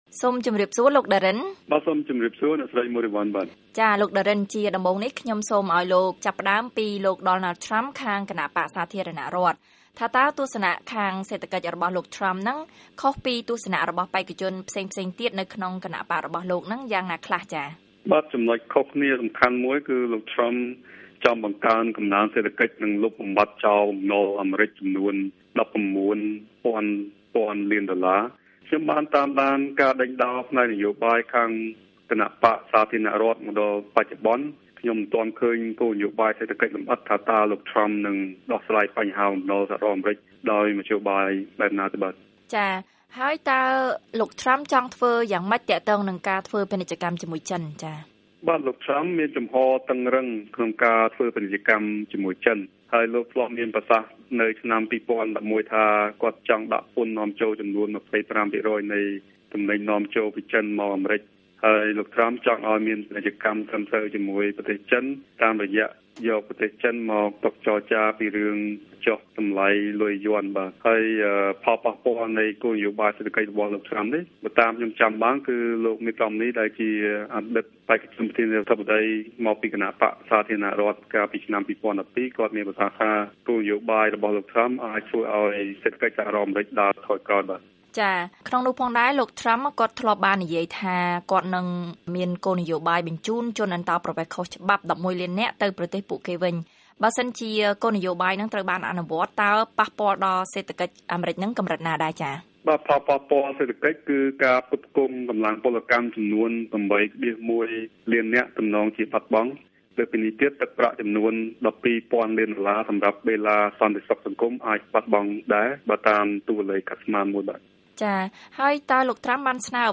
បទសម្ភាសន៍ VOA៖ បេក្ខជនឈរឈ្មោះជាប្រធានាធិបតីអាមេរិក ចង់ស្តារសេដ្ឋកិច្ចអាមេរិកជាថ្មី